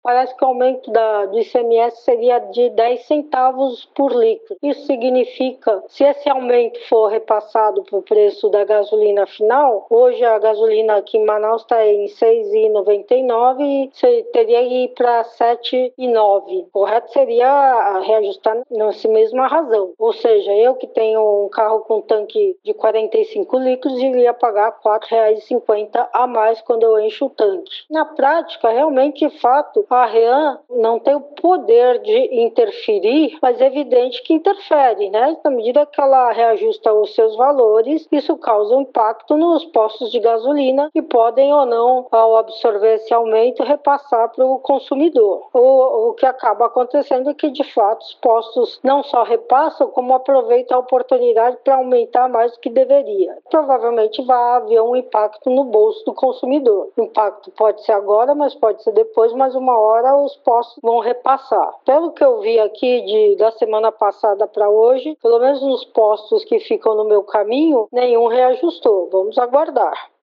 Ela explica como o reajuste pode influenciar o valor final nos postos e afetar diretamente o bolso do consumidor.